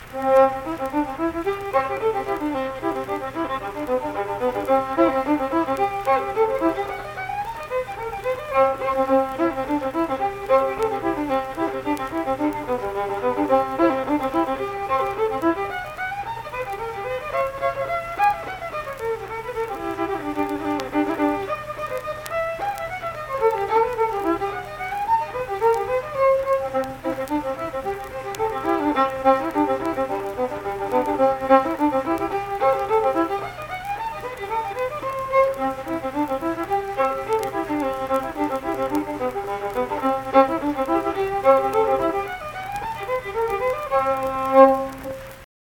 Unaccompanied vocal and fiddle music
Instrumental Music
Fiddle
Saint Marys (W. Va.), Pleasants County (W. Va.)